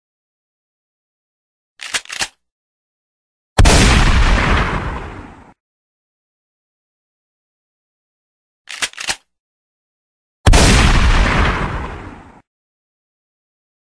جلوه های صوتی
دانلود صدای دو لول از ساعد نیوز با لینک مستقیم و کیفیت بالا